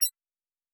pgs/Assets/Audio/Sci-Fi Sounds/Interface/Digital Click 03.wav at master
Digital Click 03.wav